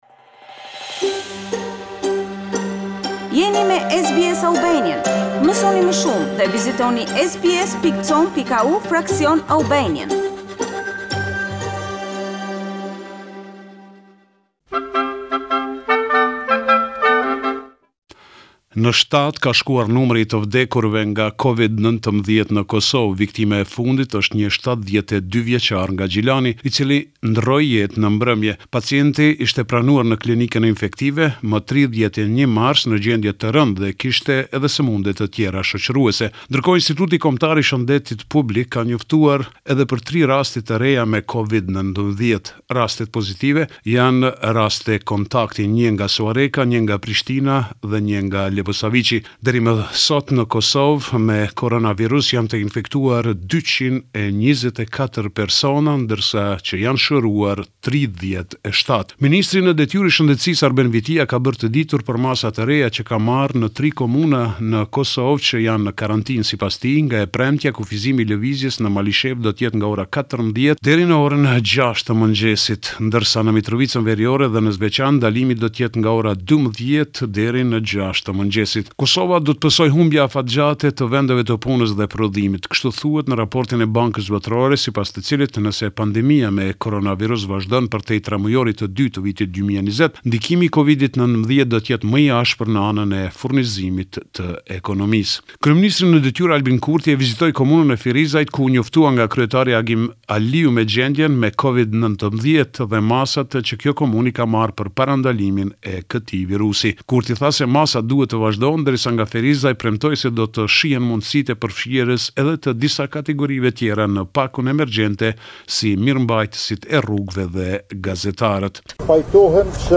This is a report summarising the latest developments in news and current affairs in Kosovo.